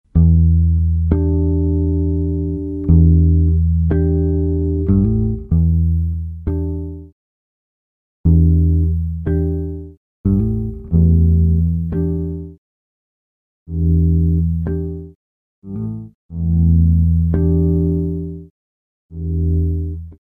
Let loose with this indispensable noise-gate for building drum tracks with bite.
Each sequence is in a dry version and then effected in different ways.
Quick Gate.mp3